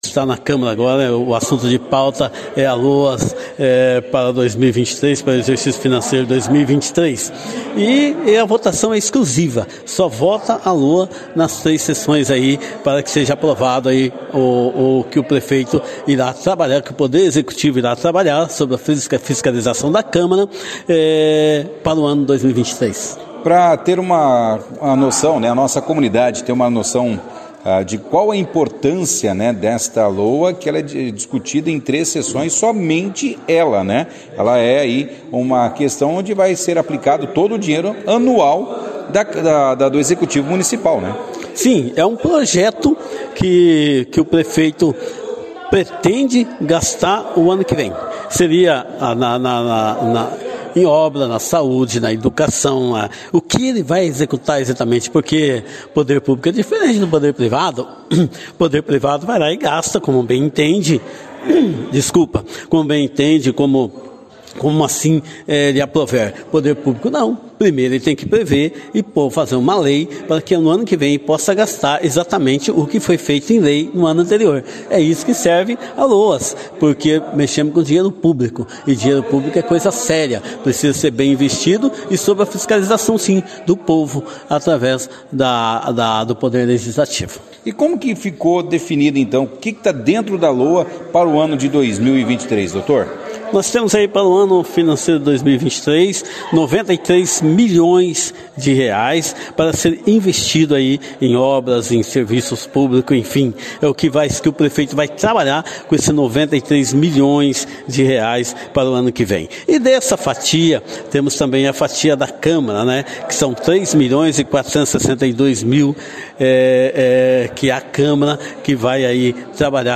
A sessão foi destaque da 2ª edição do Jornal Operação Cidade desta terça-feira, 20/09, com a participação do vereador Castro, que discorreu sobre a (LOA), e de um assunto que dominou, as explicações pessoais dos edis, que foi o barramento da entrada de Vereadores na reinauguração do ginásio de esportes do Chinelão na última semana.